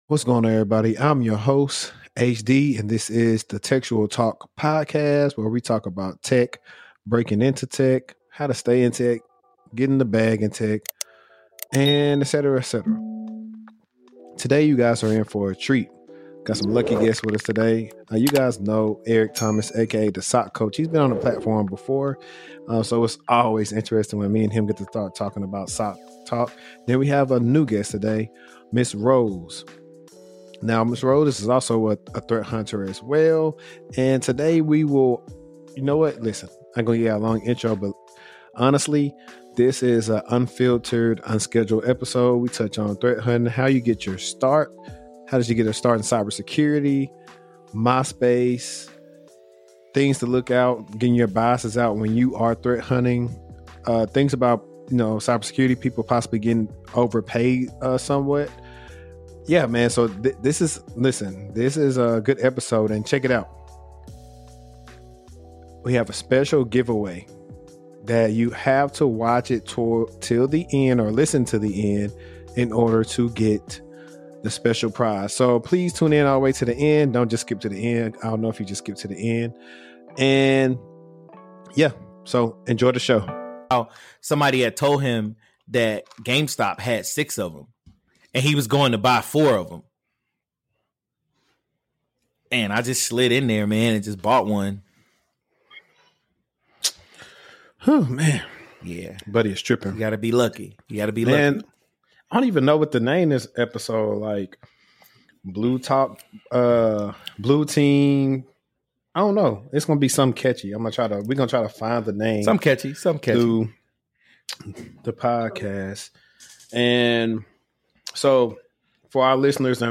This conversation hits on some important topics and also some funny ones as well. Tune in if you want to hear from 3 cybersecurity professionals